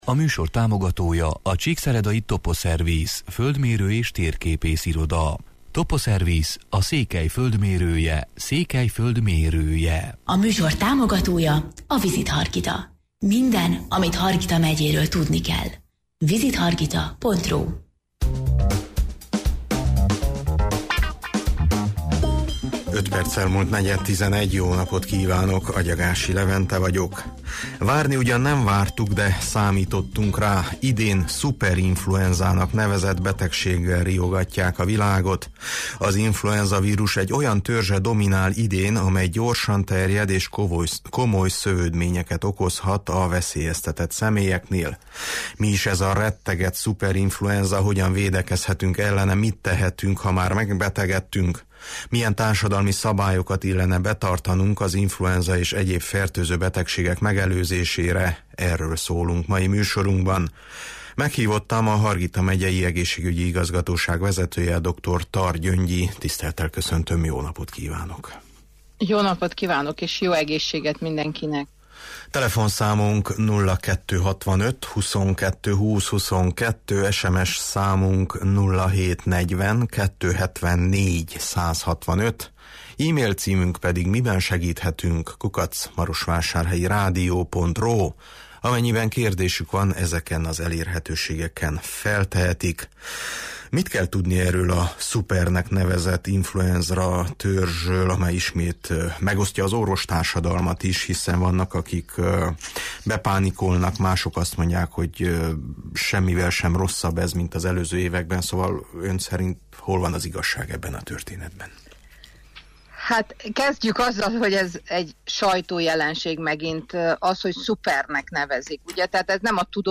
Meghívottam a Hargita Megyei Egészségügyi Igazgatóság vezetője, dr. Tar Gyöngyi: